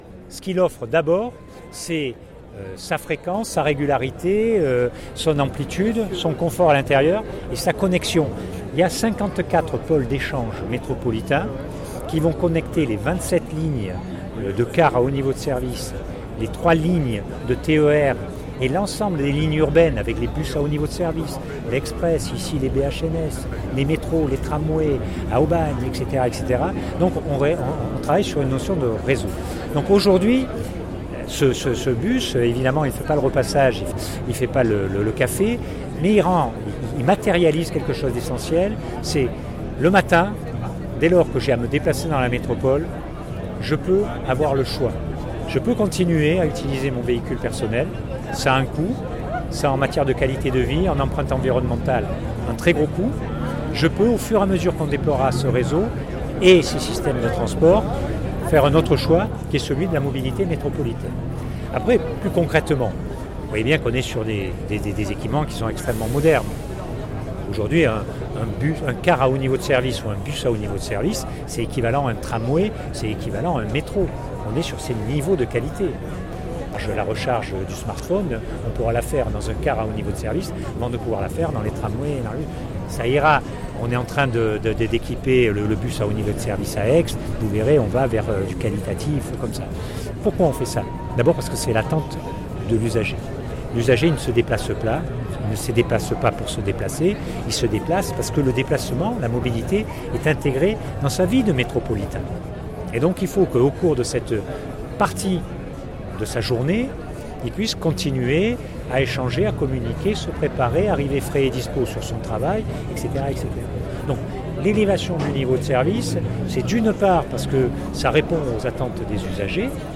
son_copie_petit-267.jpgJean-Pierre Serrus, conseiller métropolitain en charge des transports rappelle l’importance de la mobilité. Entretien.
jean-pierre_serrus_le_bus_12_09_2018.mp3